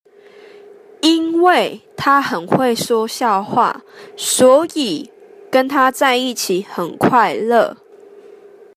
(請先仔細聆聽老師唸，再來，請你試著唸唸看。)